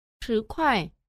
sứ khoai